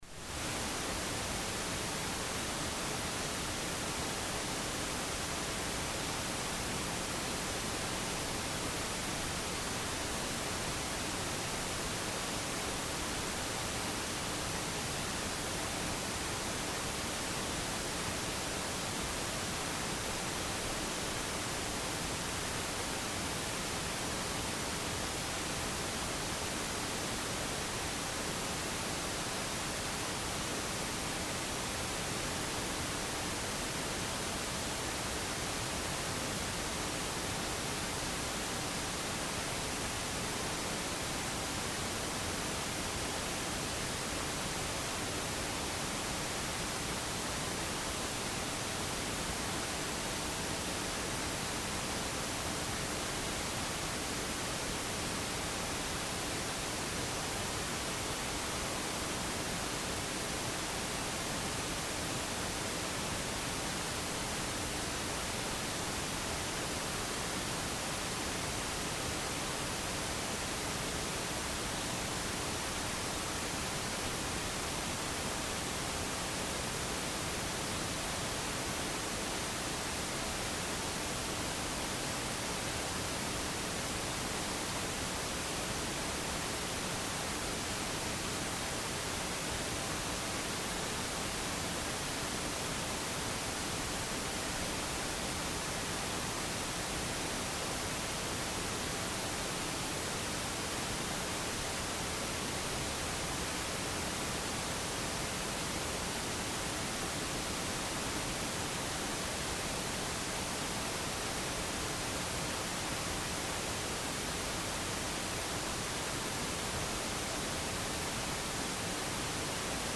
Bible Baptist Church Sermon Archive for December, 2025
Bible Baptist Church, Sermon Archive for December, 2025